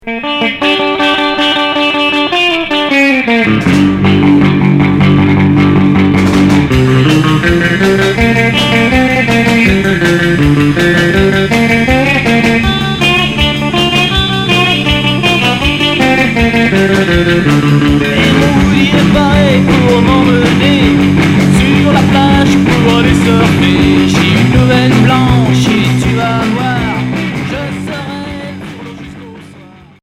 Surf